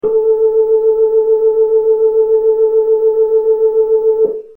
To make it easy to do some signal analysis, each recording consists of only a single note, the A above middle C, with only the 8′ drawbar pulled out. This gives an almost pure 440Hz sine wave.
Full vibrato with new capacitors. The amplitude fluctuations aren't as extreme any more, but the little sub-cycle is still visible.
new-cap-vibrato.mp3